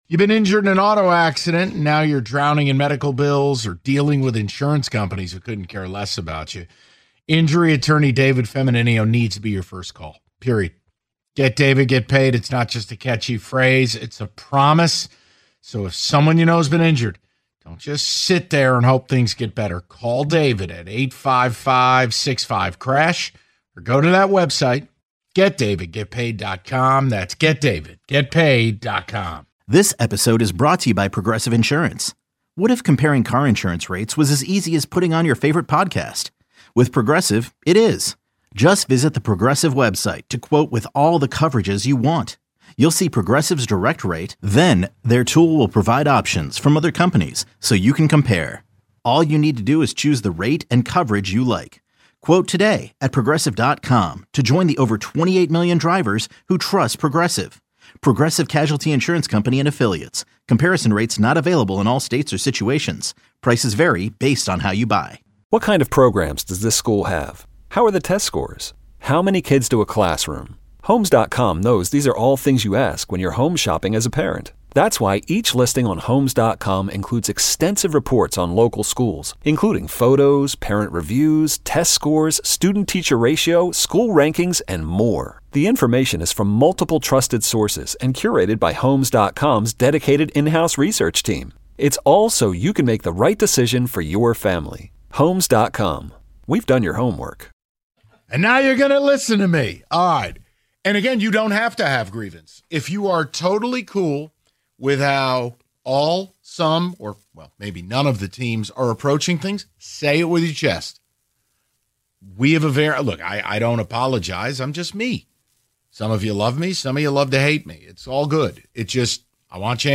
Taking Your Calls On The Urgency Among Detroit Teams The Valenti Show Audacy Sports 3.8 • 1.1K Ratings 🗓 5 August 2025 ⏱ 8 minutes 🔗 Recording | iTunes | RSS 🧾 Download transcript Summary The guys hear from the people on which Detroit teams need to be more urgent.